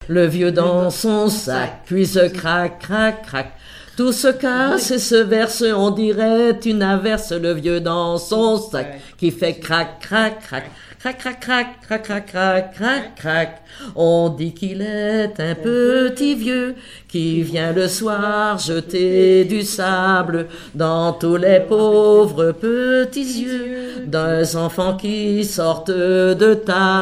berceuse
collectif de chanteuses de chansons traditionnelles
Pièce musicale inédite